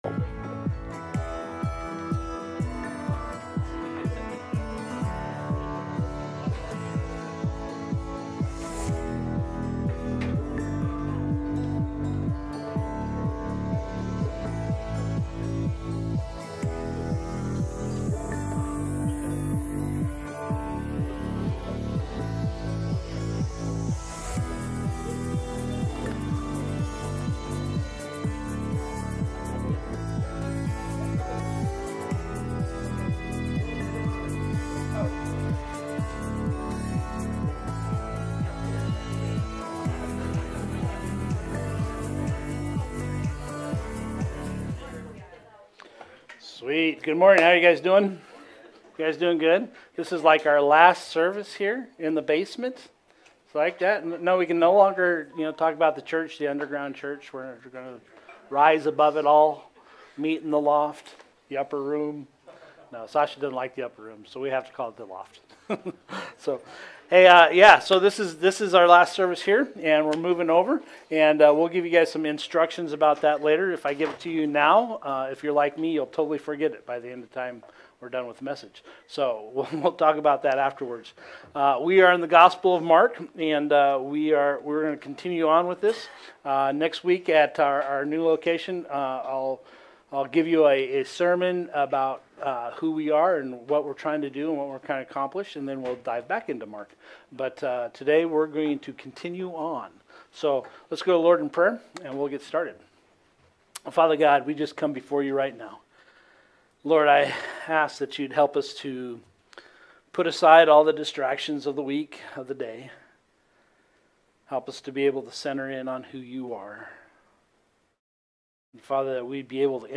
May 18th Sermon